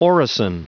Prononciation du mot orison en anglais (fichier audio)
Prononciation du mot : orison